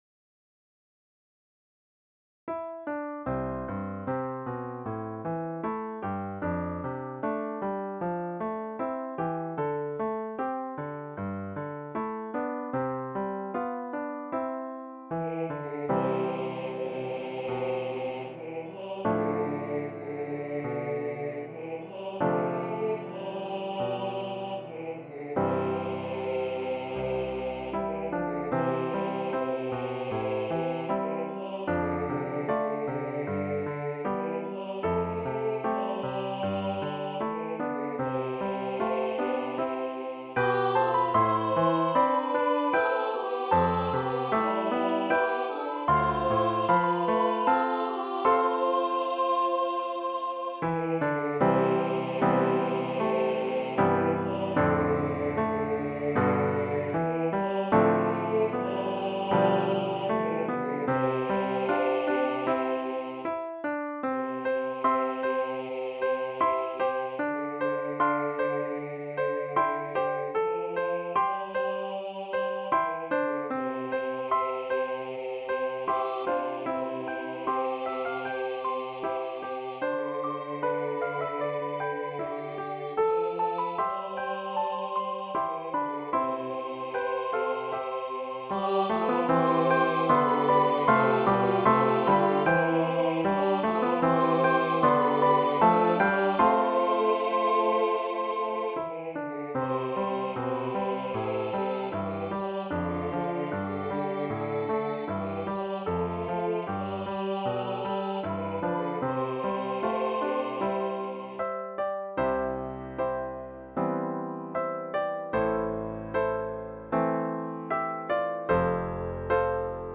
Voicing/Instrumentation: SAB